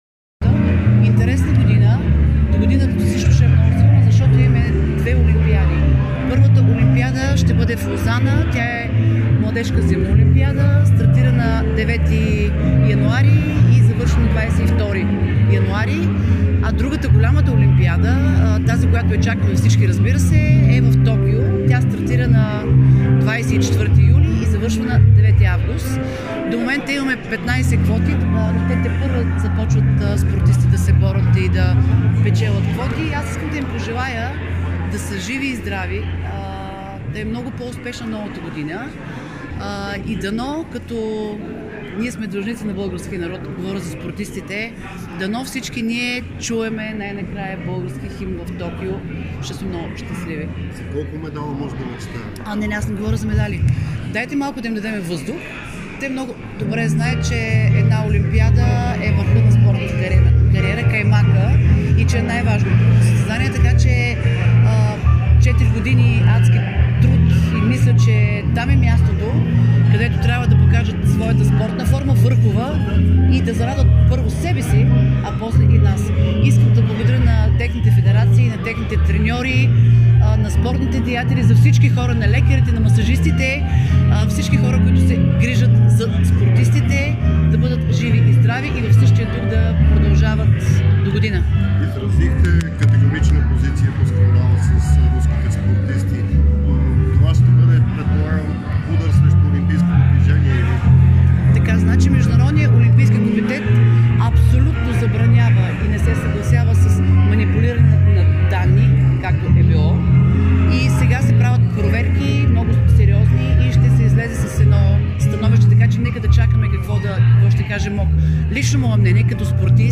Легендата в леката атлетика присъства на раздаването на наградите „Спортни Икари“.